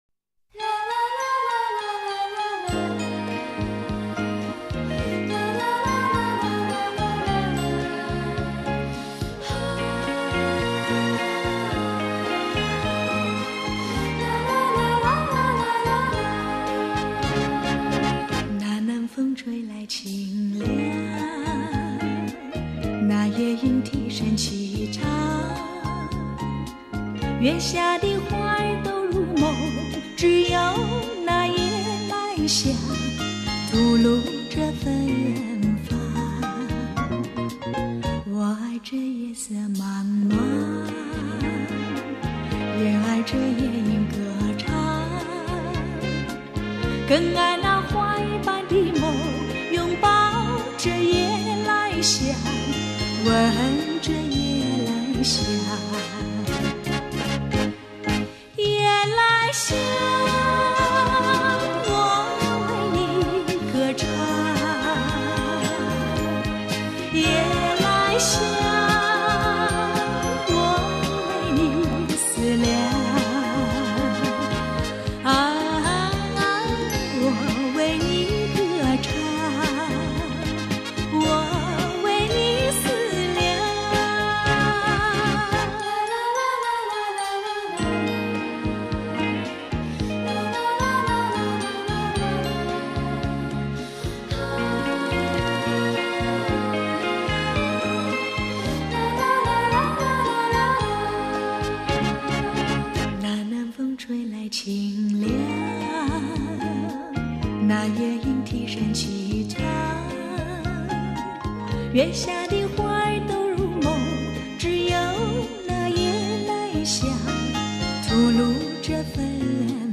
Recorder
Popular Chinese Song